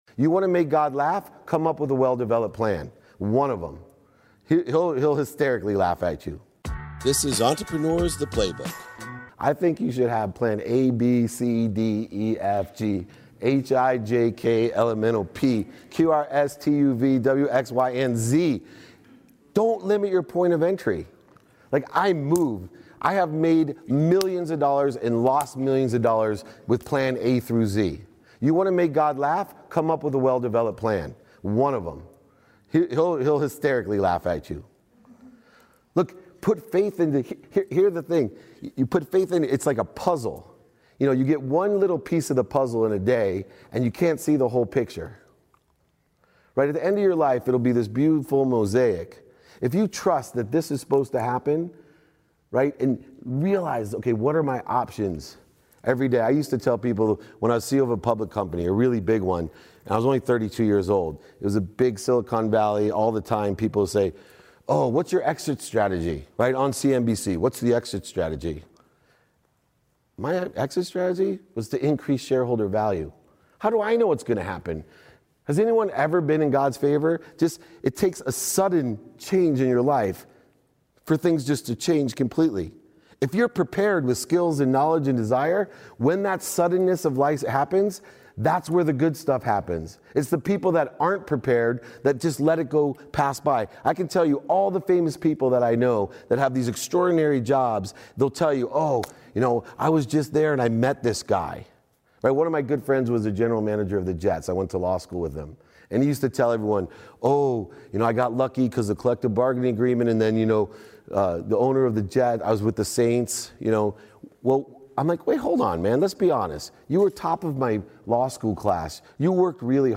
This is from a talk with college students giving them career advice on pursuing their dream jobs and if they should have a backup plan.